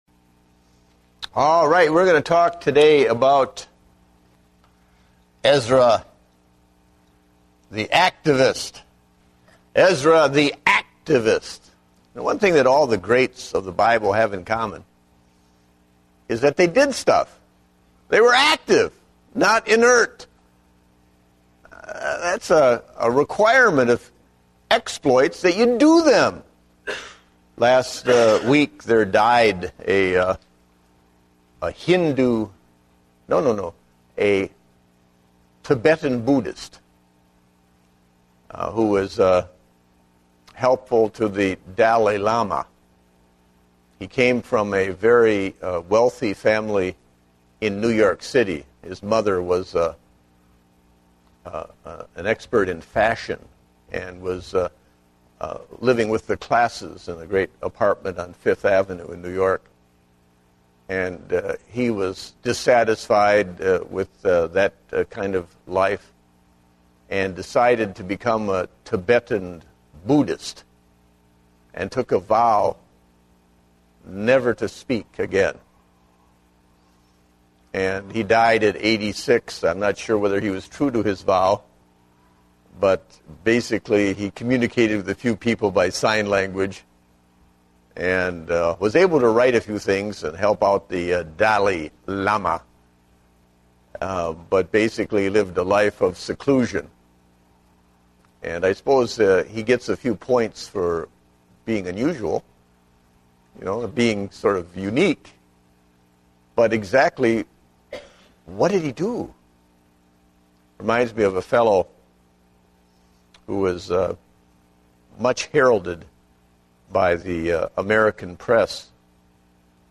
Date: May 23, 2010 (Adult Sunday School)